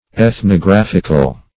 Ethnographic \Eth`no*graph"ic\, Ethnographical
\Eth`no*graph"ic*al\, . a. [Cf. F. ethnographique.]
ethnographical.mp3